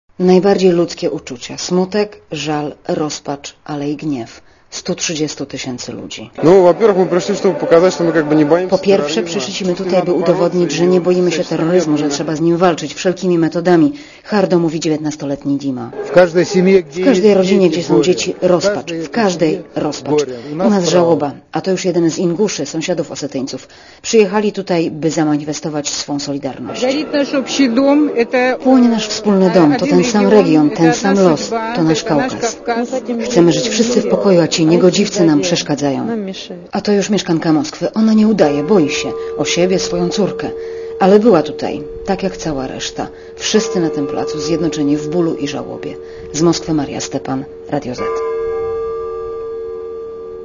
Ponad sto tysięcy ludzi zebrało się wczoraj w centrum Moskwy, na placu u stóp Kremla.
osetia-demo_w_moskwie.mp3